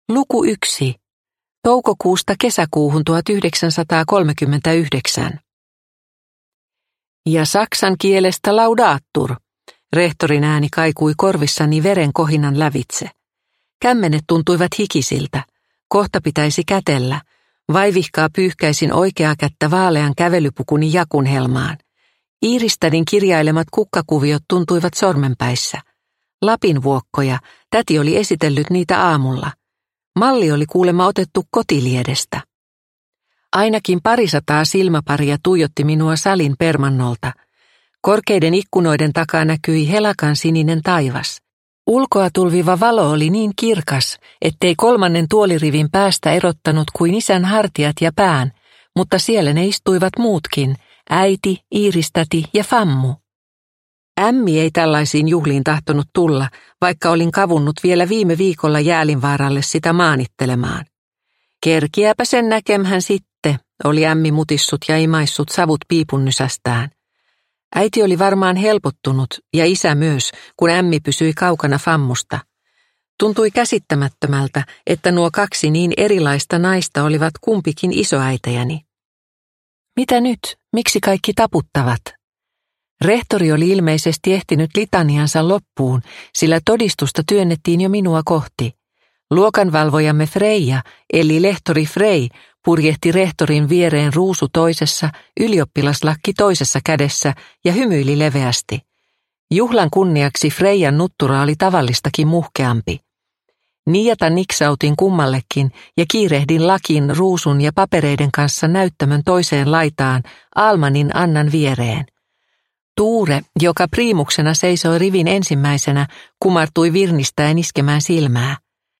Lapinvuokko – Ljudbok